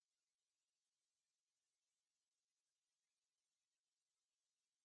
ambience_robin.ogg